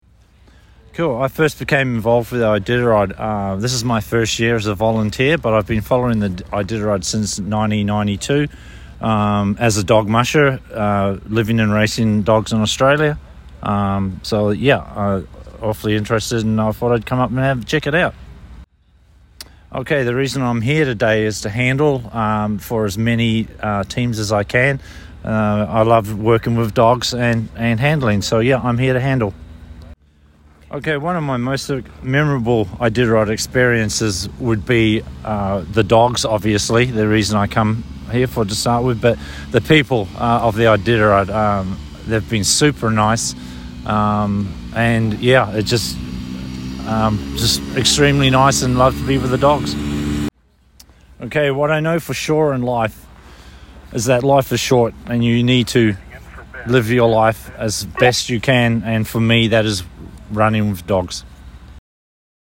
Current Location: Willow Community Center